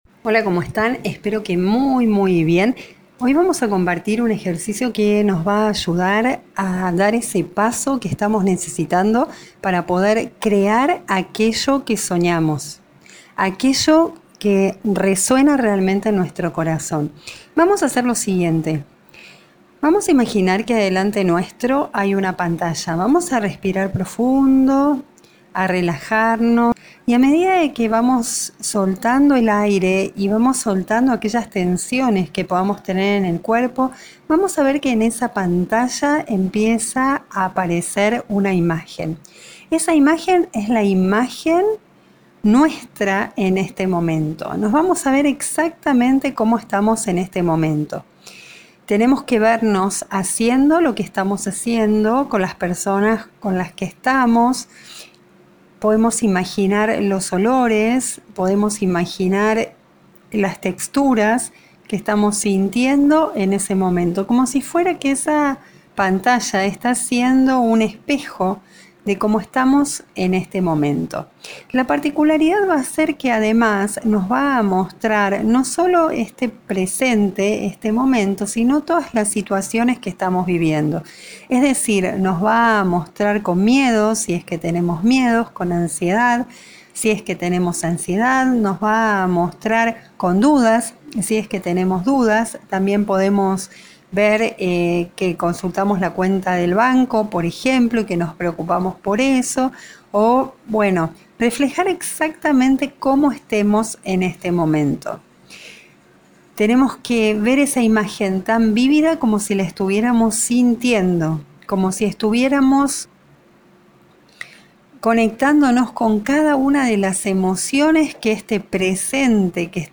En este audio te vamos a guiar en un ejercicio que te permitirá empezar a delinear cambios en tu vida para crear aquello que deseas con amor y felicidad.